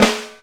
KL.TROMMEL 2.wav